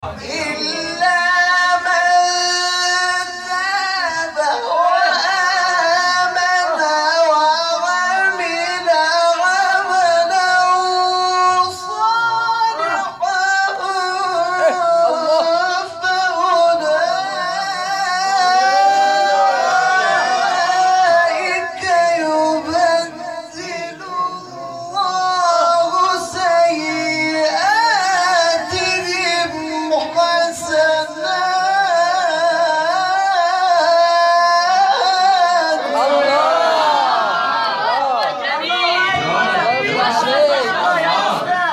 گروه شبکه اجتماعی: مقاطعی صوتی از تلاوت قاریان ممتاز کشور ارائه می‌شود.